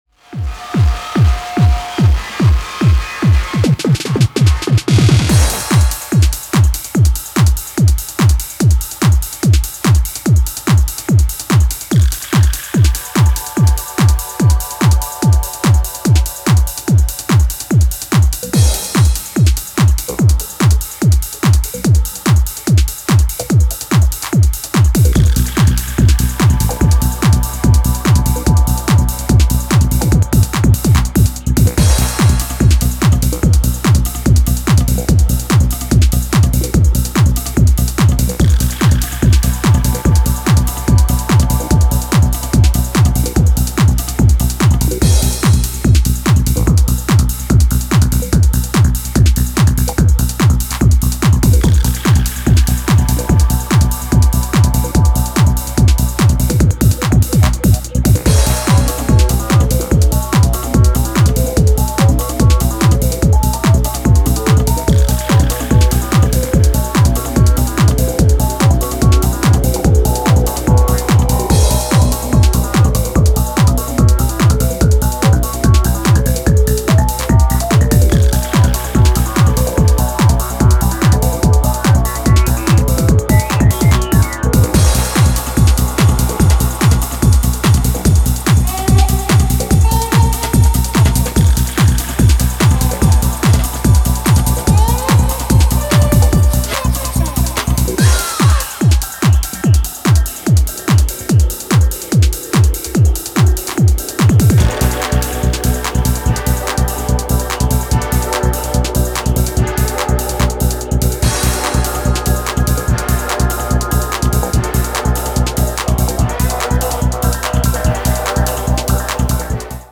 supplier of essential dance music
House Techno Breaks